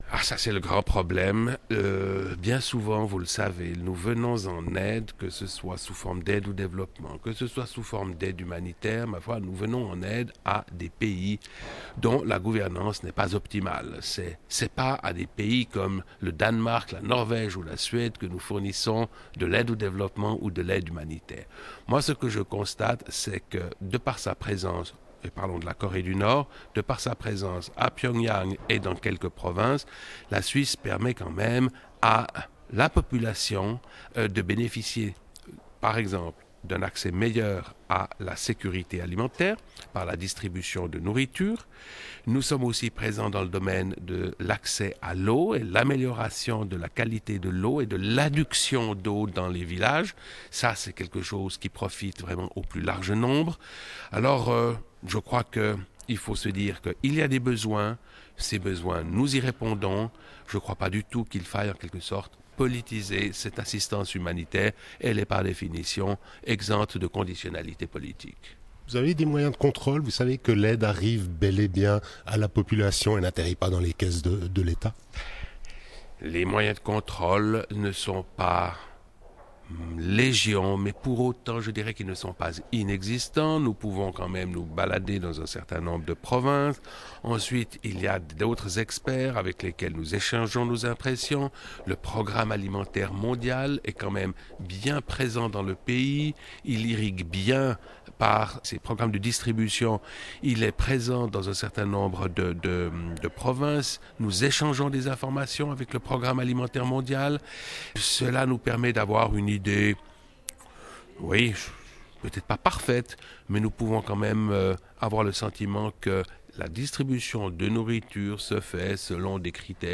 L'ambassadeur Blaise Godet s'exprime sur le bien-fondé de l'aide à un pays totalitaire